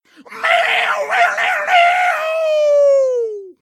mouth-guitar_02